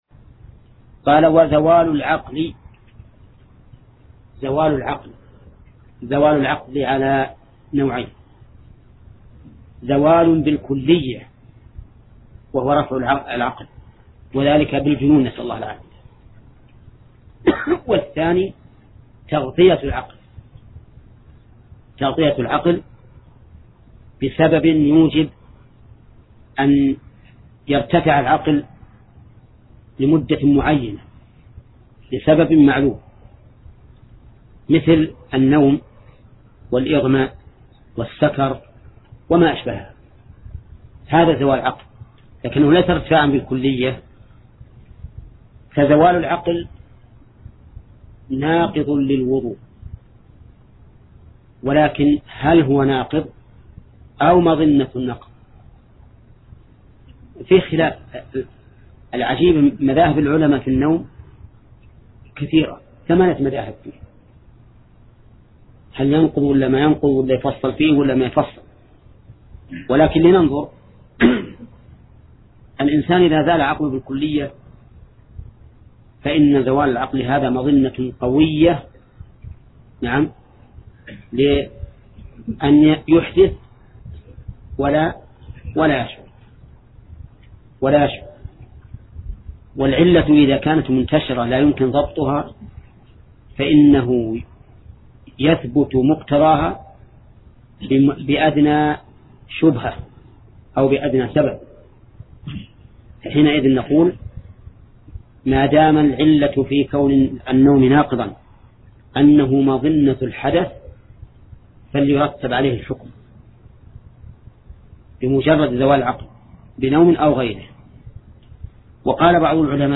درس (14): باب نواقض الوضوء